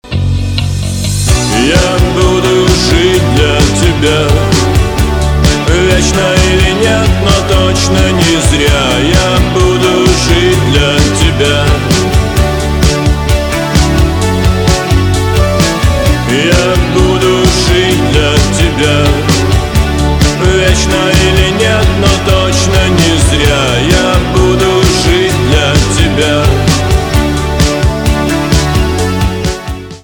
пост-панк
гитара , барабаны , чувственные